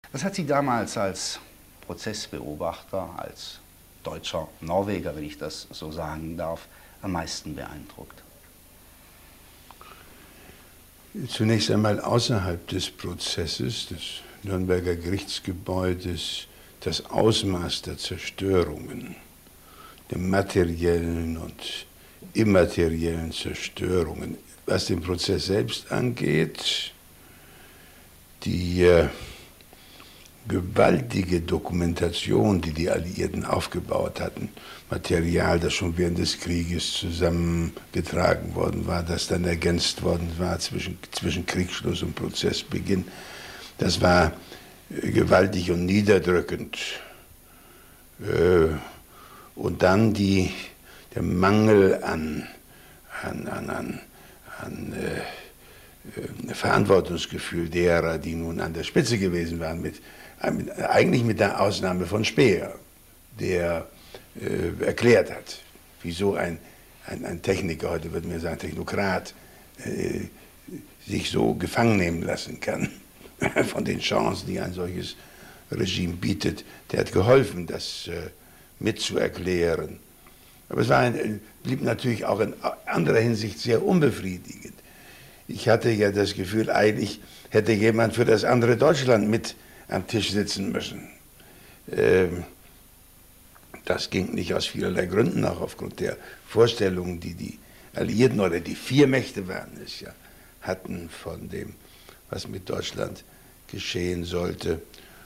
Excerpt from an interview with Willy Brandt